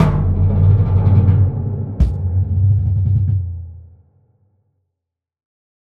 Big Drum Hit 24.wav